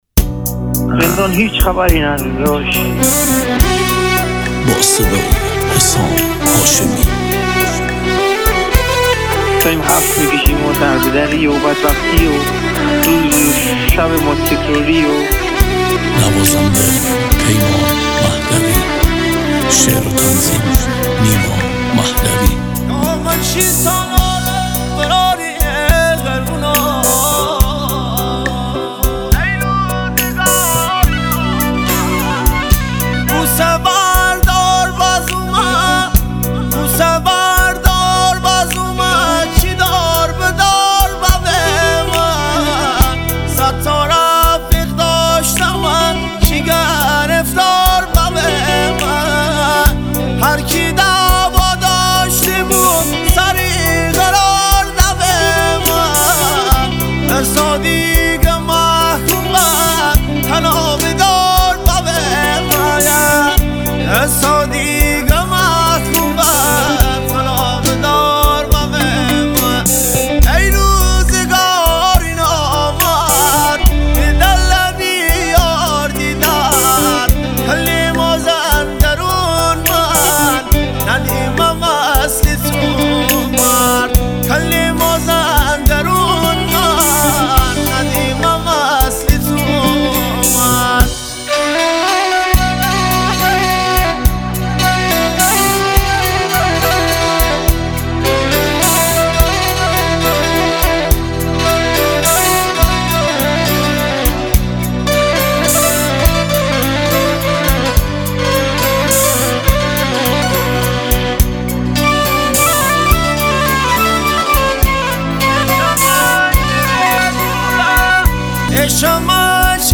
دانلود آهنگ مازندرانی